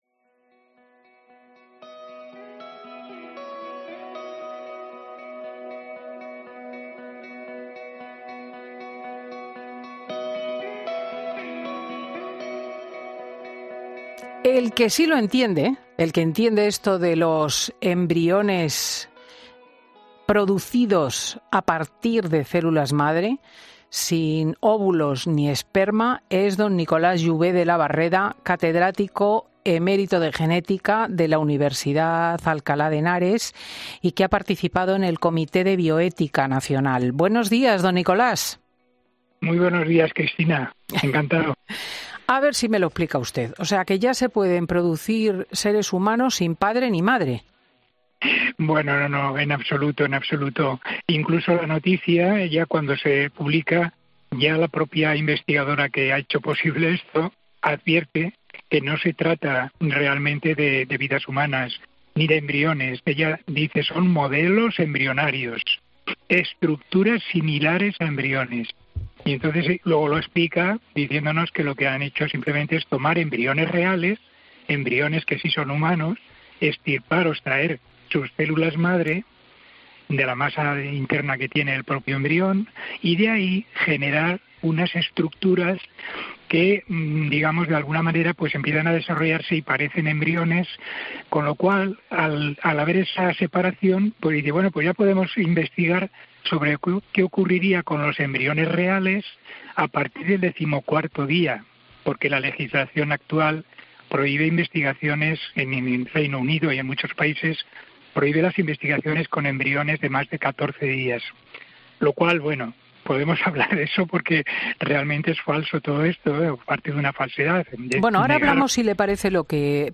En 'Fin de Semana' hablamos con un experto en genética que nos explica los límites y el futuro de este método que se desarrolla en Reino Unido y Estados Unidos